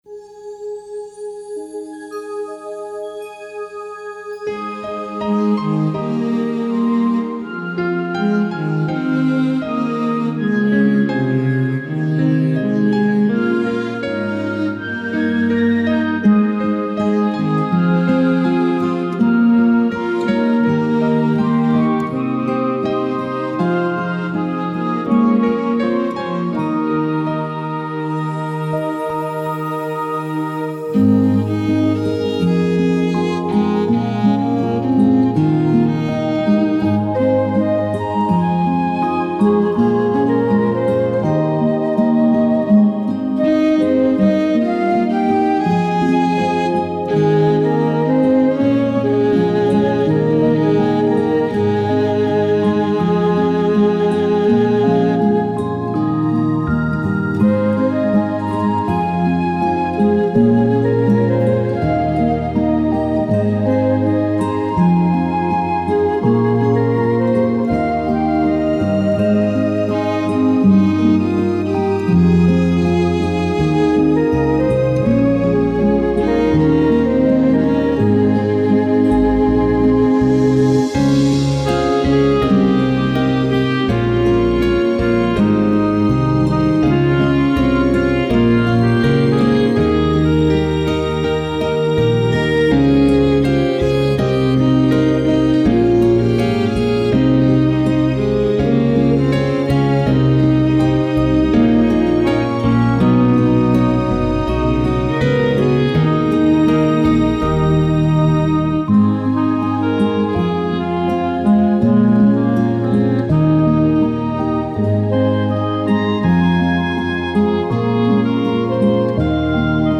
Initially, I worked with an arranger to create these lush arrangements.
7-only-tears-instrumental.mp3